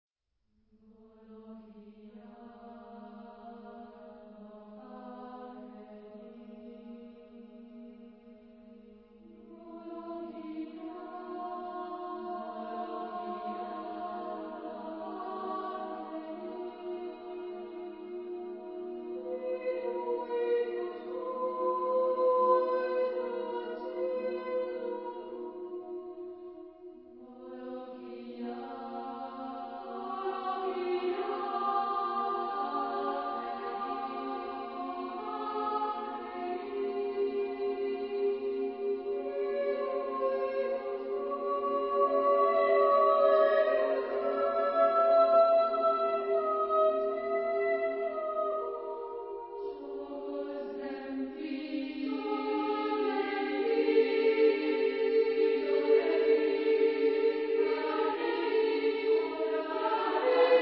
Genre-Stil-Form: weltlich
Charakter des Stückes: deprimierend ; andante
Chorgattung: SSSSAA  (6 Frauenchor Stimmen )
Solisten: Sopranos (2)  (2 Solist(en))